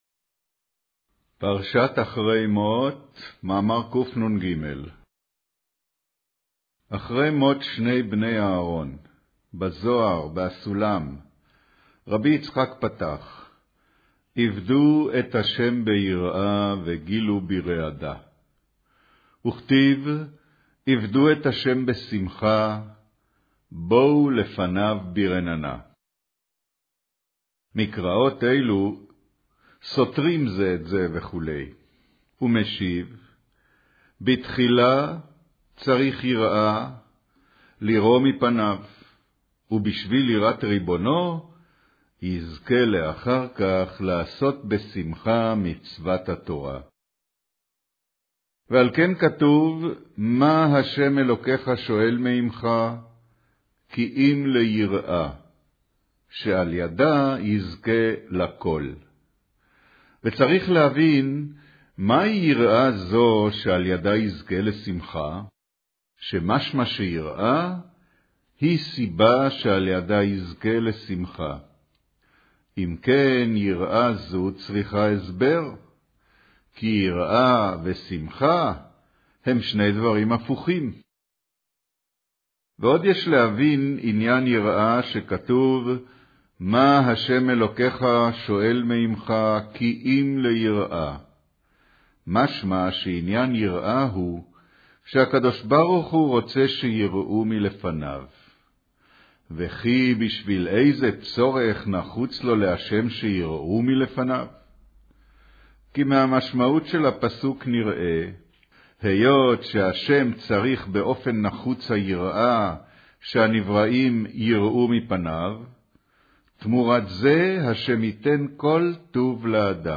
אודיו - קריינות